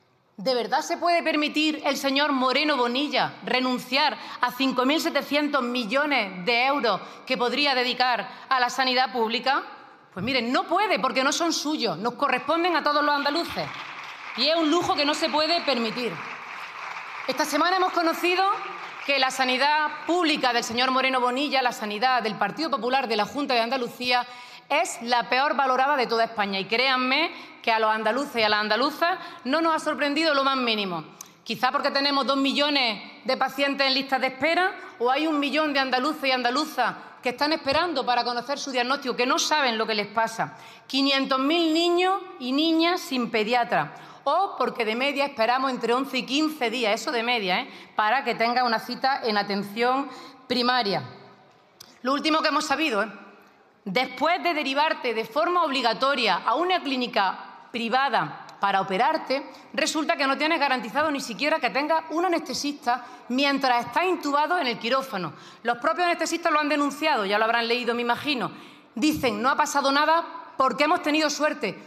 En una intervención en el Congreso sobre sanidad pública, Cobo acusó al PP de hundir la sanidad pública andaluza, “con 2 millones de pacientes en listas de espera, 1 millón de andaluces esperando para conocer un diagnóstico, 500.000 niños y niñas sin pediatra y entre 11 y 15 días de espera media para ser atendidos en Atención Primaria”.